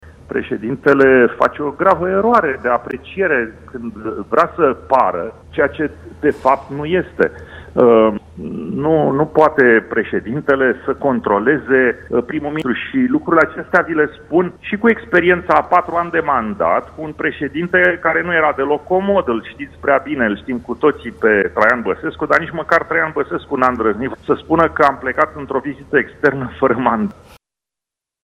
Președintele Senatului a spus în urmă cu puțin timp la Antena 3 că nici măcar Traian Băsescu nu a avut o asemenea atitudine față de guvernele sale: